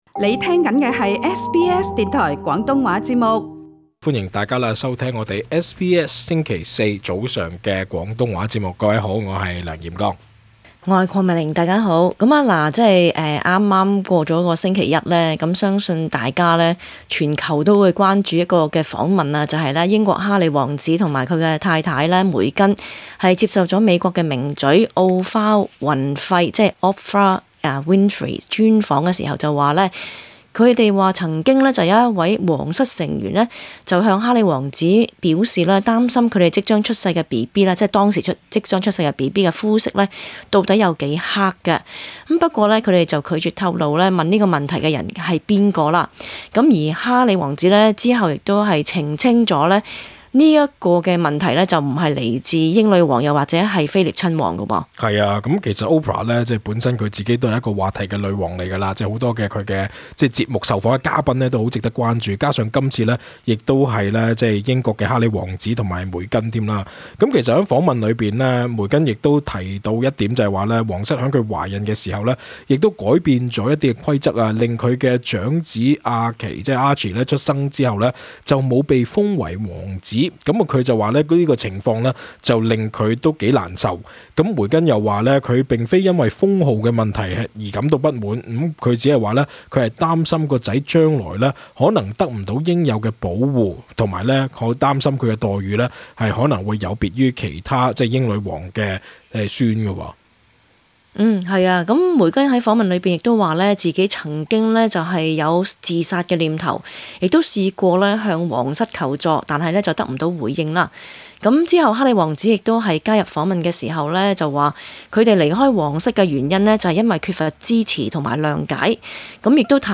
本節目內嘉賓及聽眾意見並不代表本台立場 瀏覽更多最新時事資訊，請登上 廣東話節目 Facebook 專頁 、 廣東話節目 MeWe 專頁 ，或訂閱 廣東話節目 Telegram 頻道 。